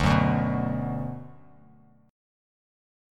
Listen to D6 strummed